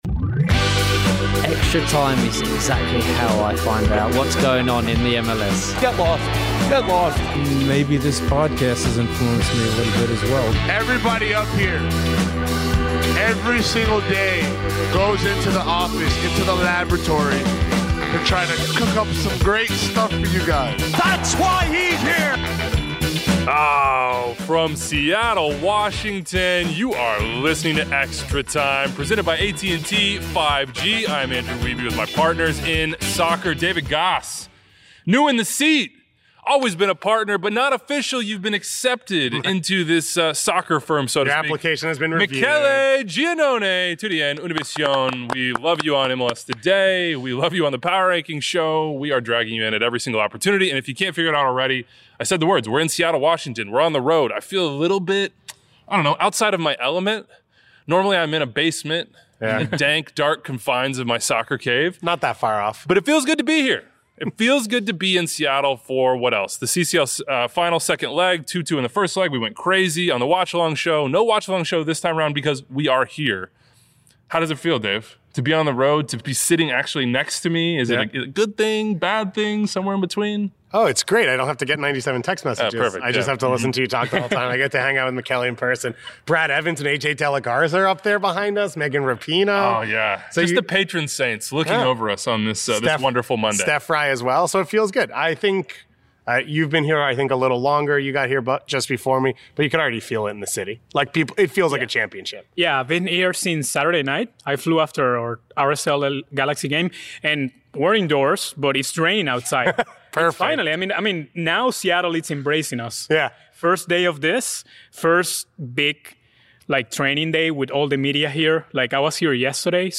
Jordan Morris Interview